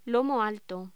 Locución: Lomo alto